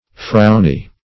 Search Result for " frowny" : The Collaborative International Dictionary of English v.0.48: Frowny \Frown"y\, a. Frowning; scowling.
frowny.mp3